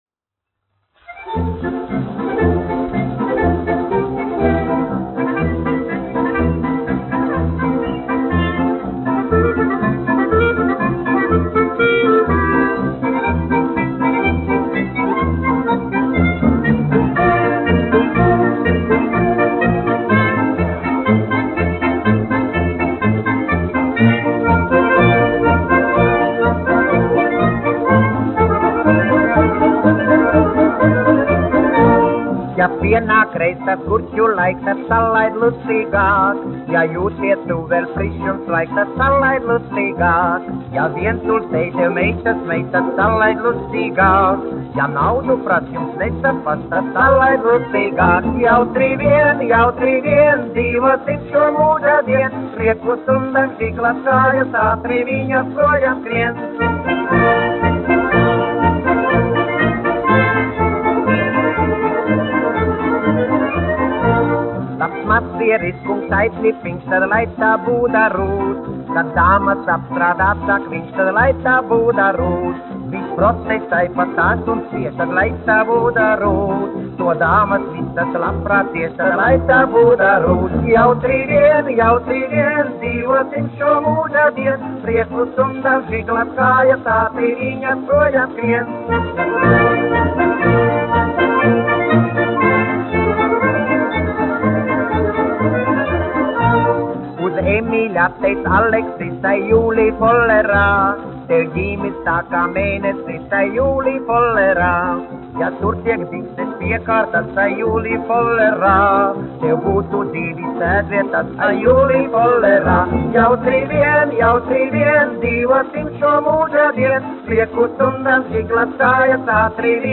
1 skpl. : analogs, 78 apgr/min, mono ; 25 cm
Populārā mūzika
Fokstroti
Skaņuplate